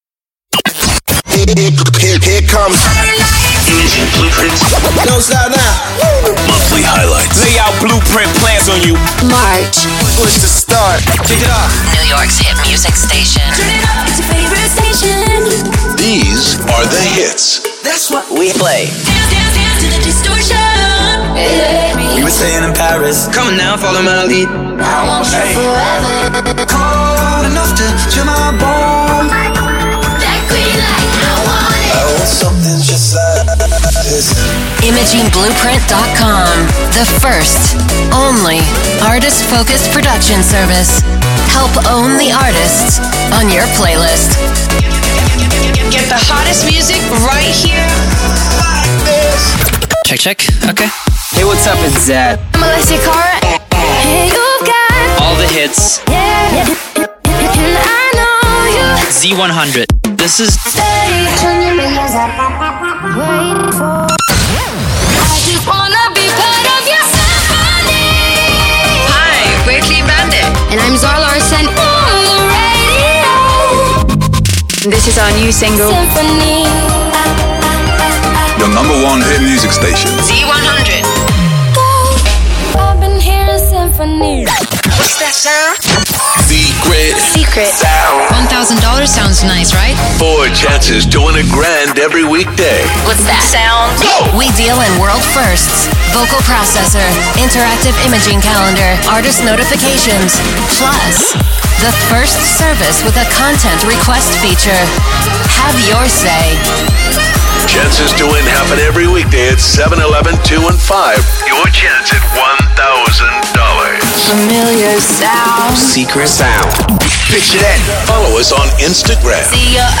Our client KIIS is used to demonstrate 'IB' production alongside the world famous Z100.